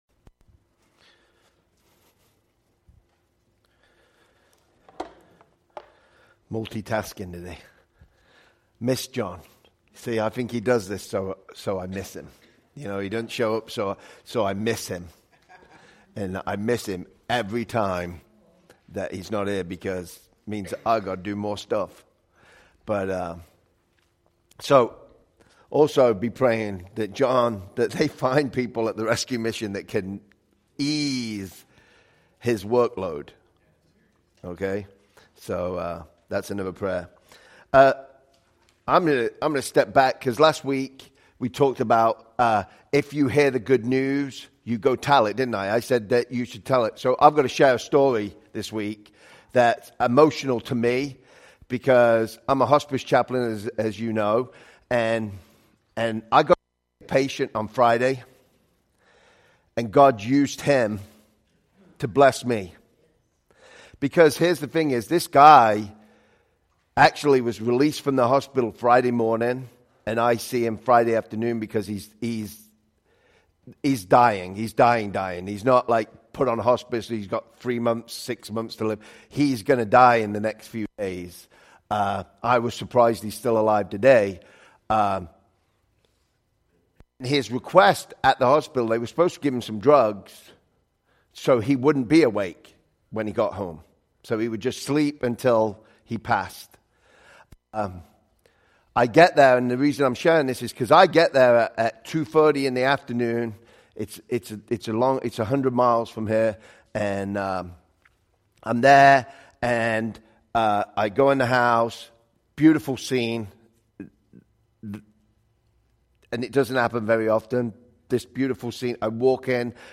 Sermons by Calvary Memorial Church Rockford